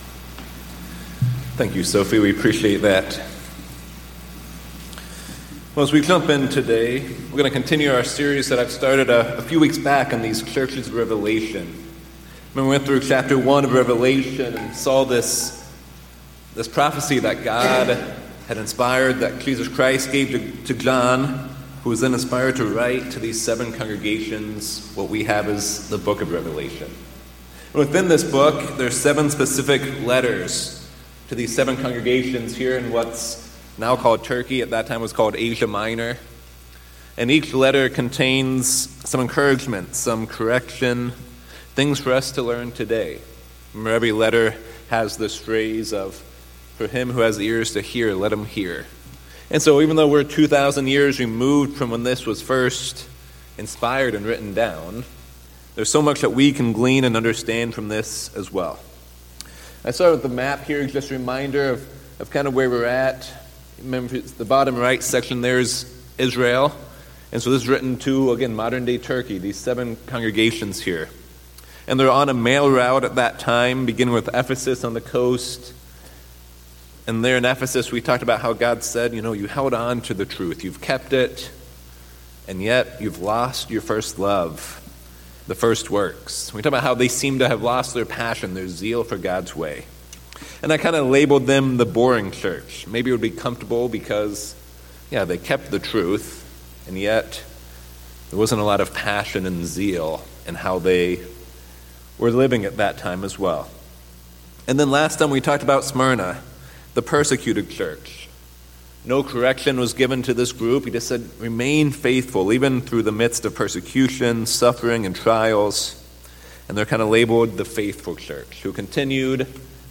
In part three of the sermon series on the seven churches, we will examine the letter to the church of Pergamos. This church is known as the compromising church, and the city was considered the center of pagan worship.